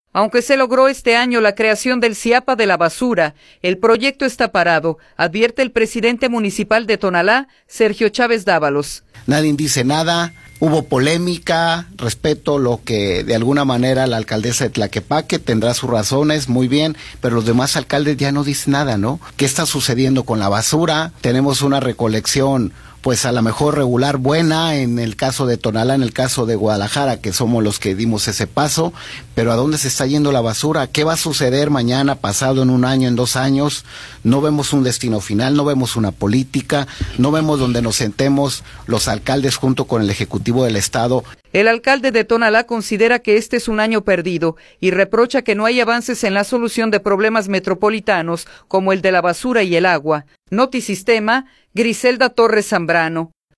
Aunque se logró este año la creación del SIAPA de la basura, el proyecto está parado, advierte el presidente municipal de Tonalá, Sergio Chávez Dávalos.